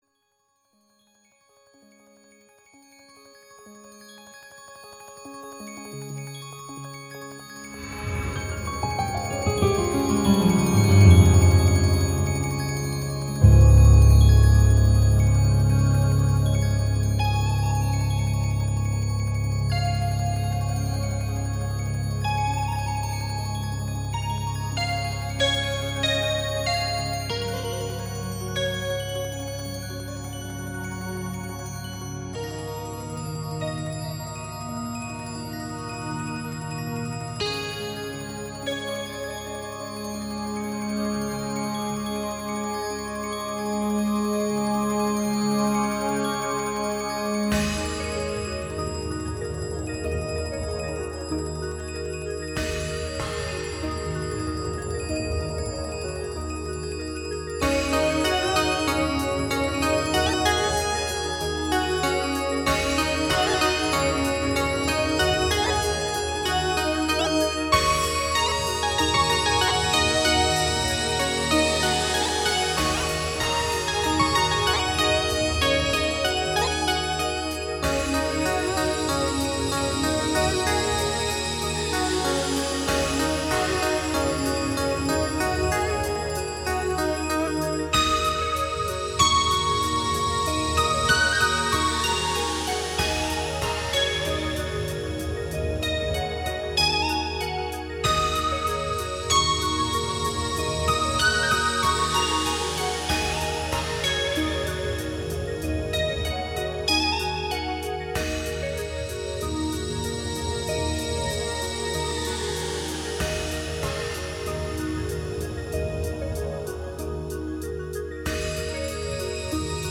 空灵音乐调制的心灵鸡汤一尘不染的音符虚无缥缈